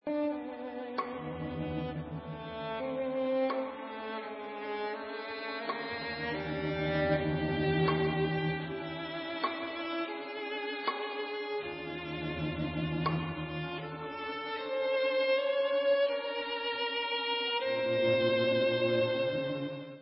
Recording: CHAMBER MUSIC
Adagio